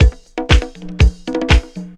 C4HOUSE116.wav